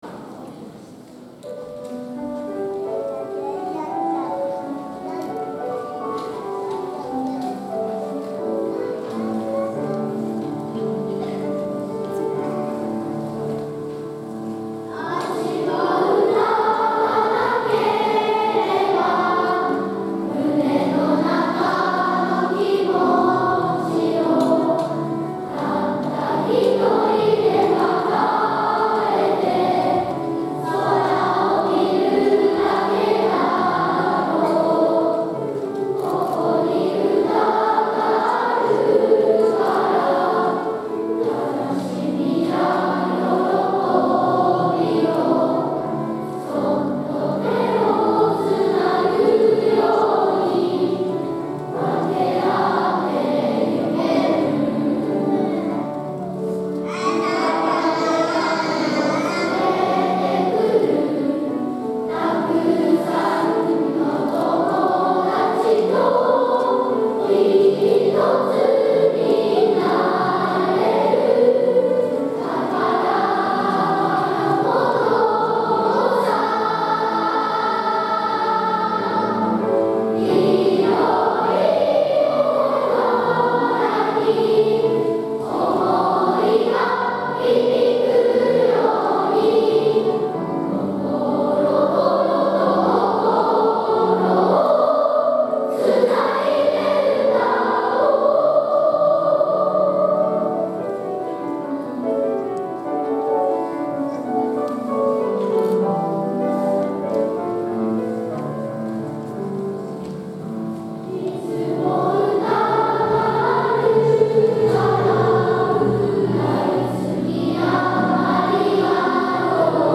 会場二部合唱「
今日も会場中のみなさんの心を一つにして歌うことができました♪